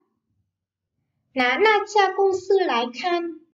Ná na cha cung sư lái khan.